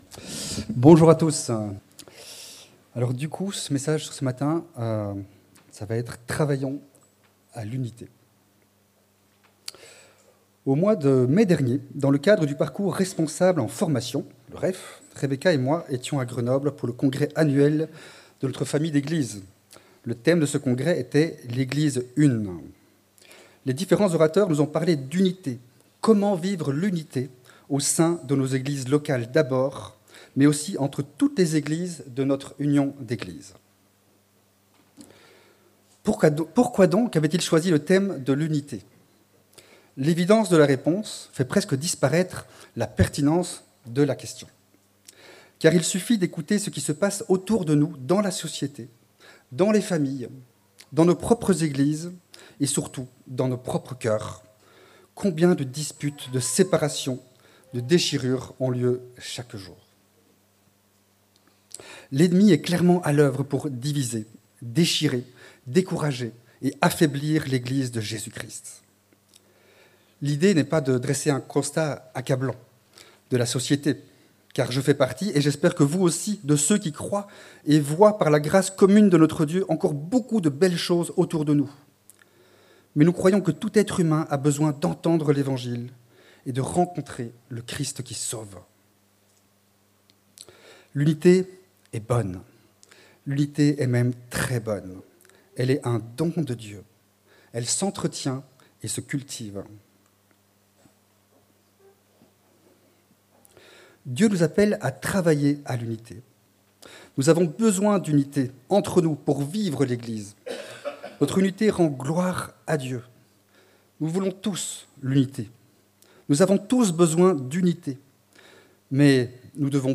Travaillons à l'unité - Prédication de l'Eglise Protestante Evangélique de Crest sur l'épître aux Ephésiens